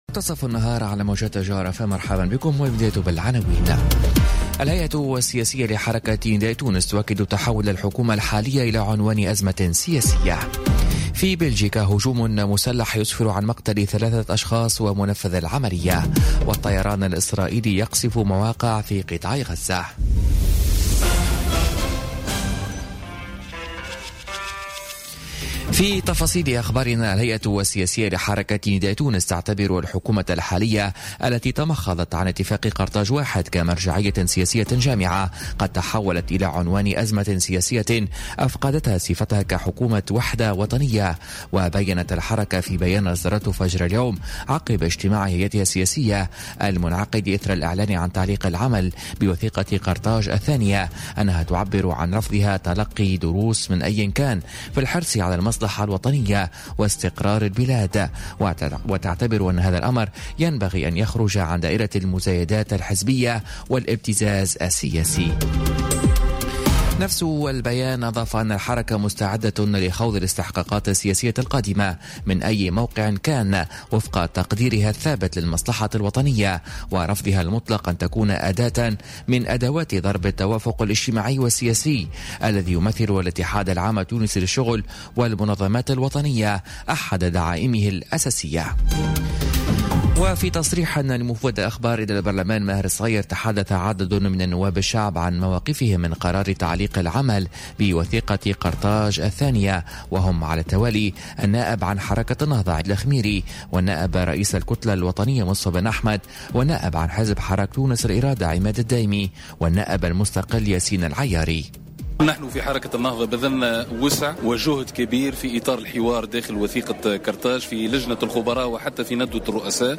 نشرة أخبار منتصف النهار ليوم الثلاثاء 29 ماي 2018